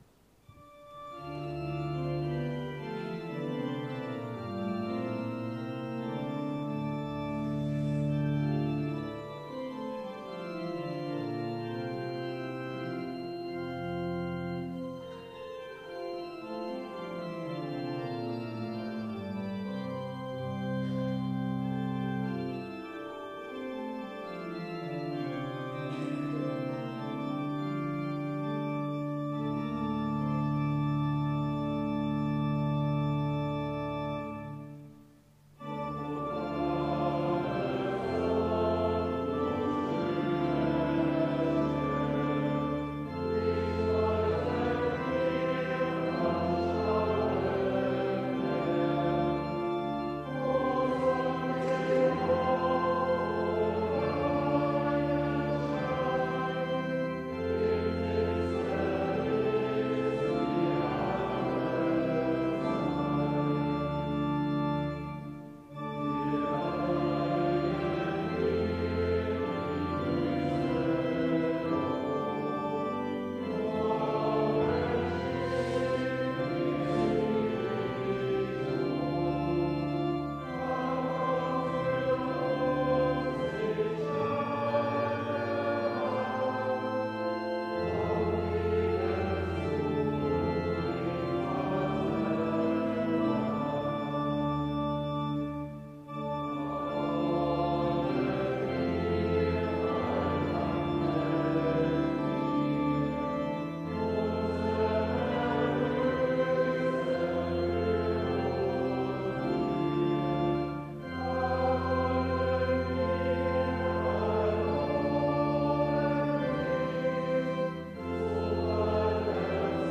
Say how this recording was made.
Audiomitschnitt unseres Gottesdienstes vom 1.Avent 2024